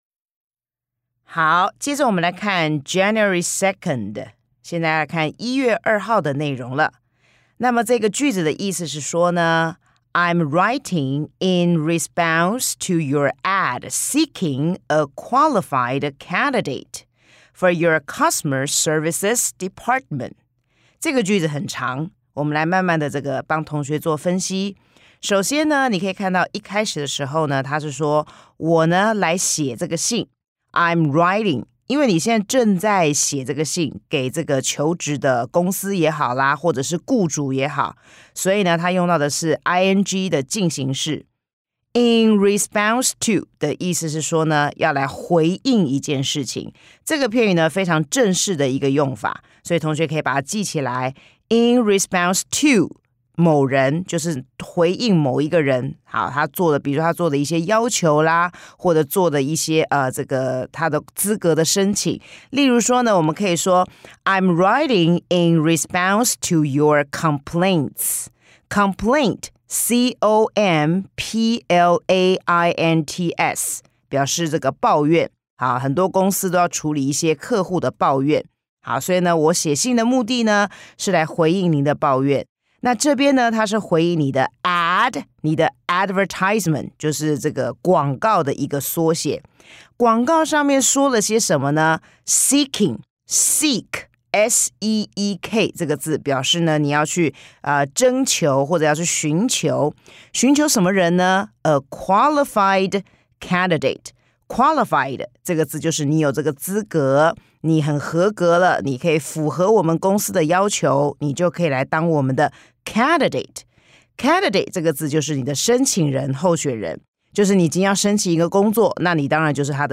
專業老師絕無冷場地講解每日課程，輕鬆養成學習態度。